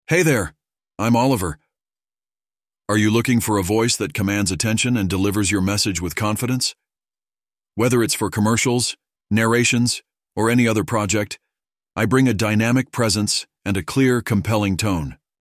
Custom voiceovers to add a personal touch